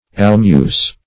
almuce - definition of almuce - synonyms, pronunciation, spelling from Free Dictionary Search Result for " almuce" : The Collaborative International Dictionary of English v.0.48: Almuce \Al"muce\, n. Same as Amice , a hood or cape.